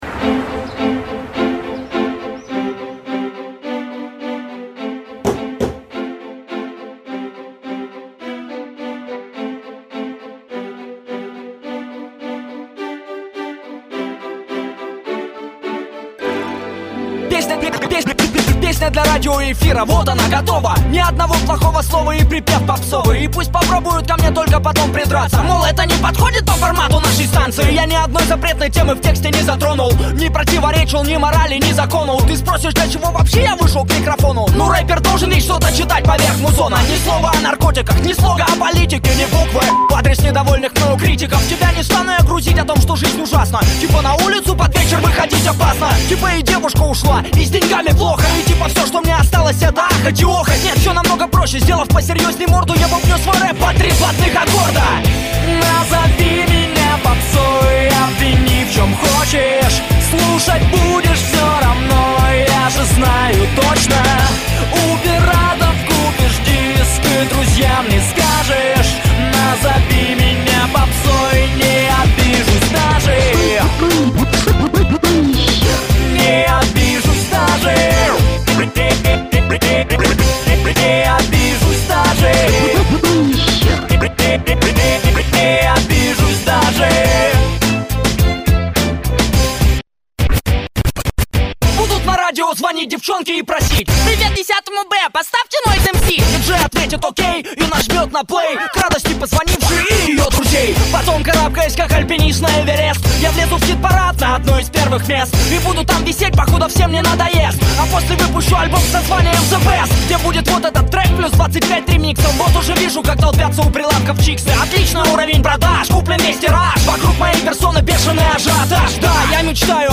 Категория: Русский рэп